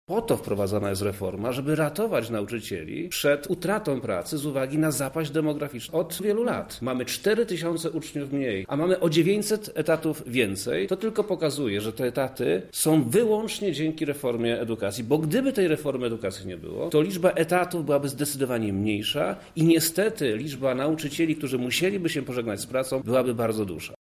Zmienia się również liczebność lubelskich szkół. O tym dlaczego tak się dzieje mówi Wojewoda Lubelski Przemysław